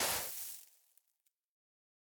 brush_sand_complete4.ogg